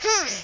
yoshi_panting.ogg